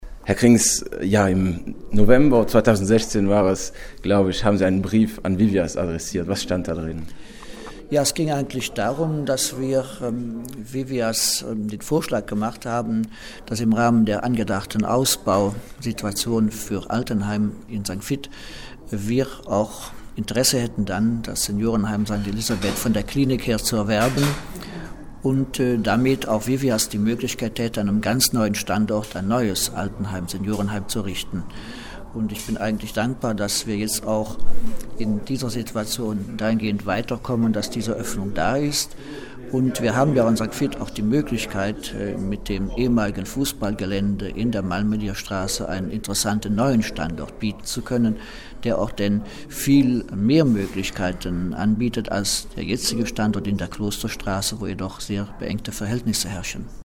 sprach auch mit St.Viths Bürgermeister Christian Krings: